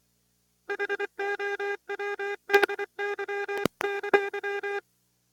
Modulation Effects
I’m using the linear audio track on my VCR, which does not get much of anything from the head drum. The capstan is rotated by a motor, which has a fly-wheel, but the tape does not move at an exactly constant speed.
VHS HIFI audio, however, is recorded as an FM track mixed with the video tracks, which gives, well, Hi Fidelity audio, basically no noise, nearly high frequency response, etc. The tape would be running at about 1.1 centimeters per second, and I will provide an audio sample of what it sounds like.